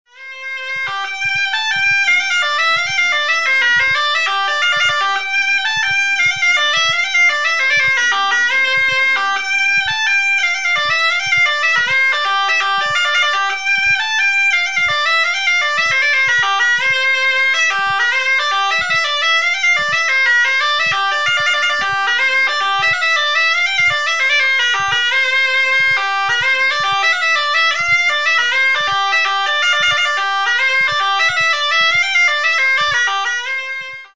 Cabrettes et Cabrettaïres le site Internet officiel de l'association de musique traditionnelle auvergnate
Les bourrées